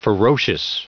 Prononciation du mot ferocious en anglais (fichier audio)
Prononciation du mot : ferocious